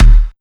176UK2TOM1-R.wav